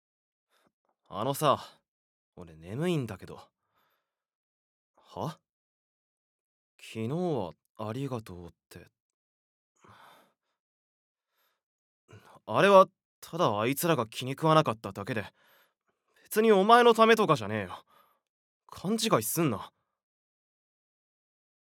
ボイスサンプル
一見冷たい男子